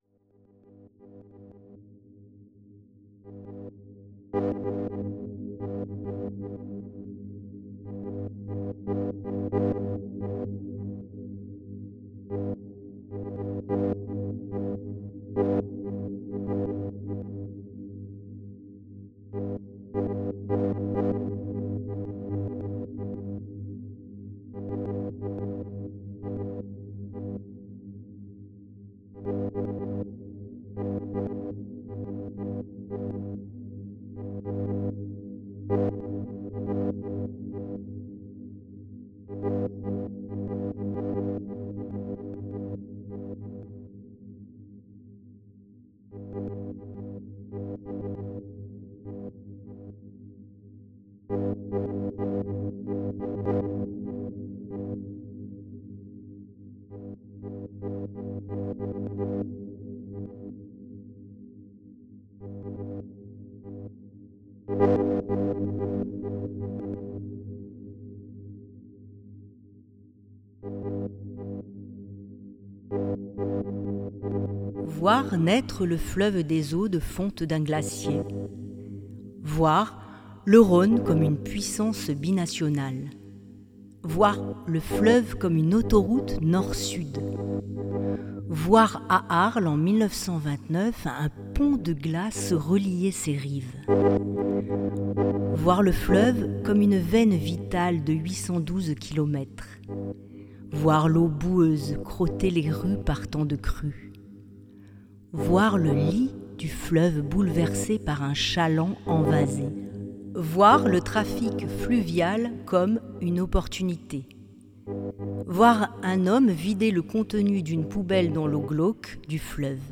Cette rencontre artistique mêle les époques, offre un parcours sensible et poétique à travers les collections du musée. Un banc sonore permet d’écouter les interprétations des textes en voix et en son.